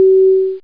Play Sheknam Barks Sound 4 - 2 (Lower) - SoundBoardGuy
sheknam-barks-and-dogs-sound-4_WjxCFLn.MP3.mp3